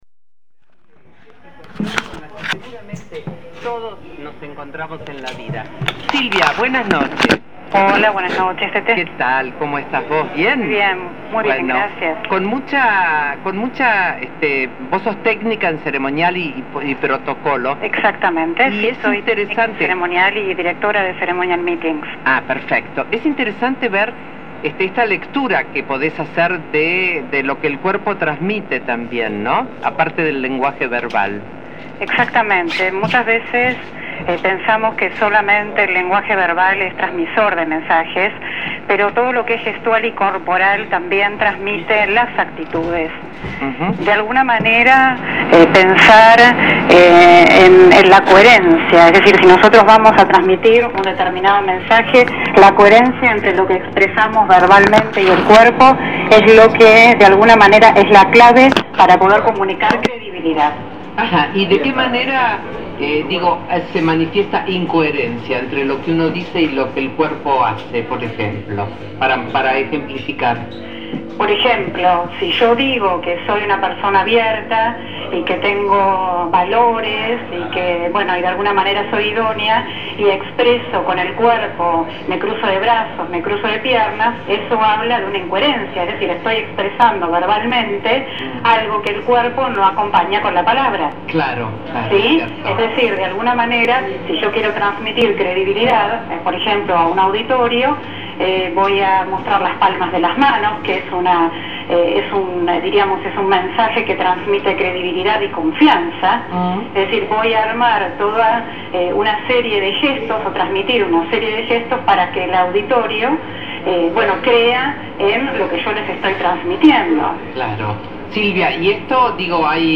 Reportaje en Radio 10 - "Qué Noche Teté" - La proxemia y el lenguaje gestual | Ceremonial Meetings